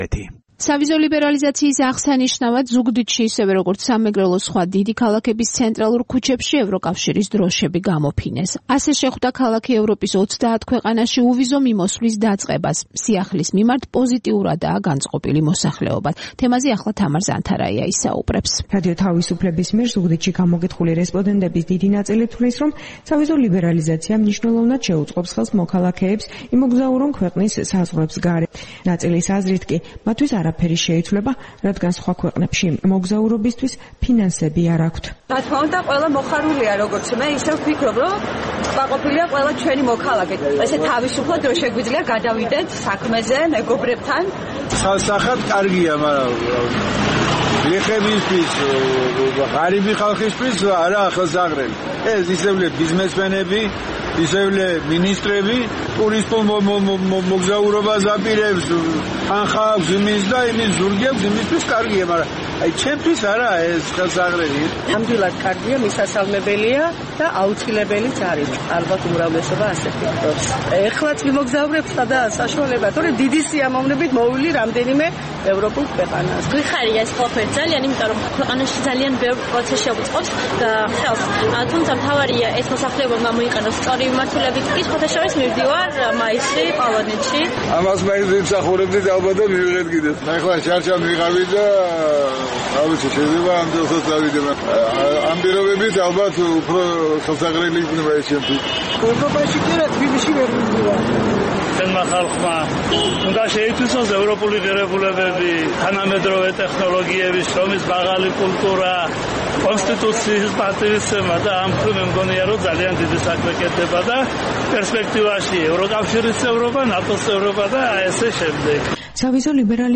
რადიო თავისუფლების მიერ ზუგდიდში გამოკითხული რესპონდენტების დიდი ნაწილი ფიქრობს, რომ სავიზო ლიბერალიზაცია მნიშვნელოვნად შეუწყობს ხელს მოქალაქეებს იმოგზაურონ ქვეყნის საზღვრებს გარეთ. ნაწილის აზრით კი, მათთვის არაფერი შეიცვლება, რადგან სხვა ქვეყნებში მოგზაურობისთვის ფინანსები არ აქვთ: